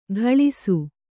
♪ ghaḷisu